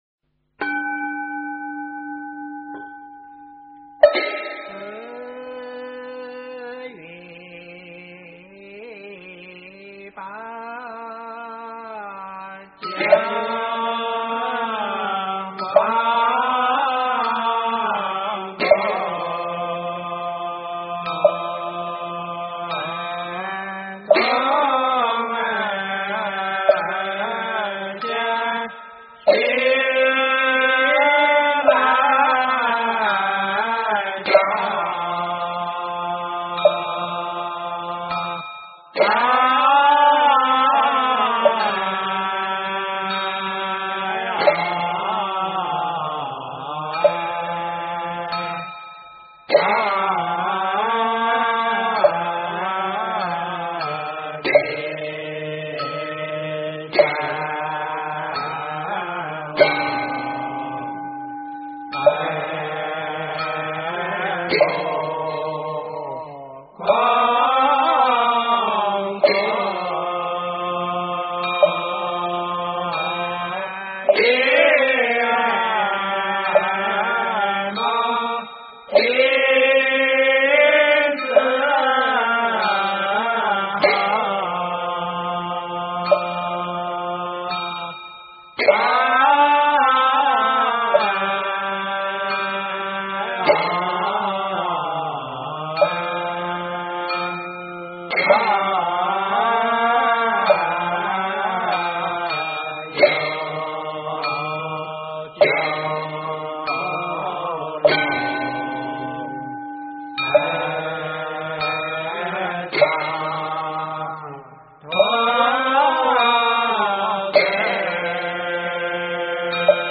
四月八--未知 经忏 四月八--未知 点我： 标签: 佛音 经忏 佛教音乐 返回列表 上一篇： 发愿文--心定和尚 下一篇： 十方一切刹--佛光山梵呗 相关文章 晚课-莲池赞 佛说阿弥陀经--未知 晚课-莲池赞 佛说阿弥陀经--未知...